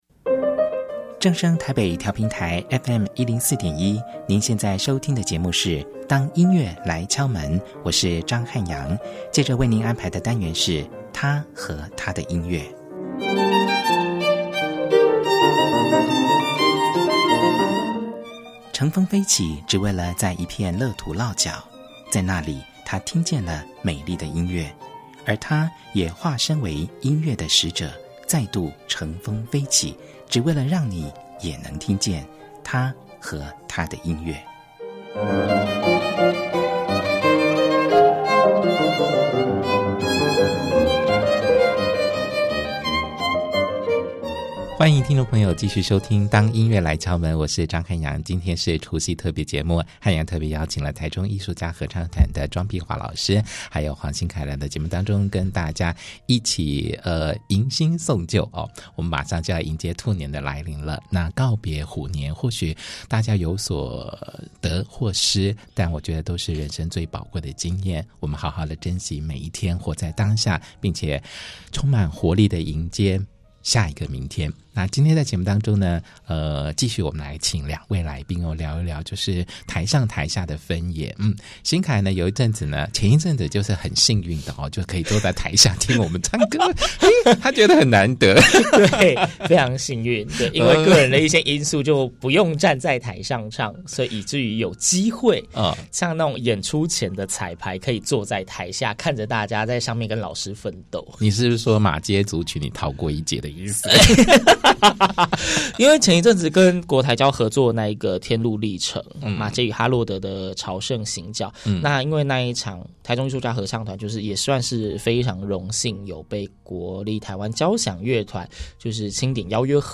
一連串的問題，都將在我們三個人的爆笑聲中得到解答。